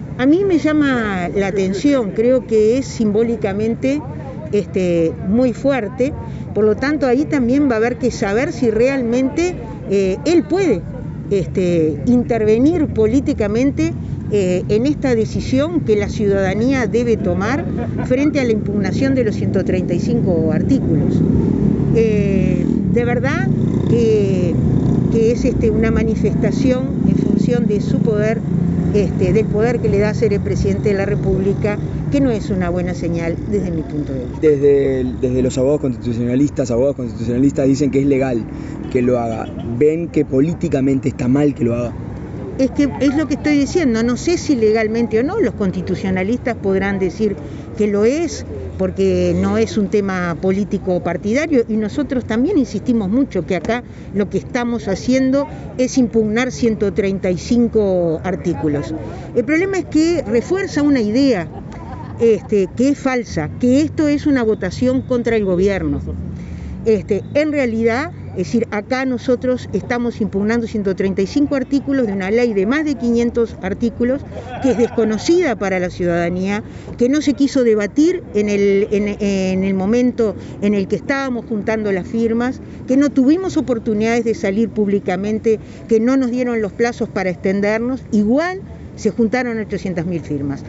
La diputada frenteamplista y exintendenta de Montevideo Ana Olivera criticó en rueda de prensa la opción de que el presidente, Luis Lacalle Pou, sea el vocero de la campaña por el No a la derogación de los 135 artículos de la Ley de Urgente Consideración (LUC).